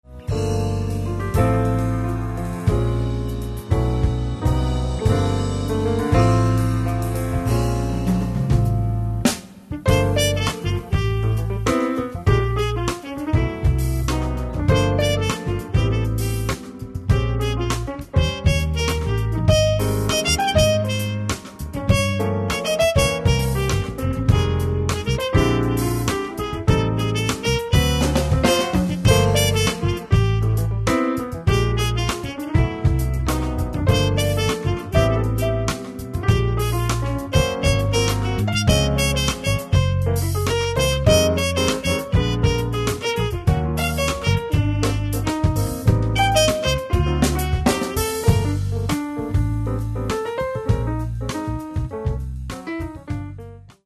Каталог -> Джаз и около -> Сборники, Джемы & Live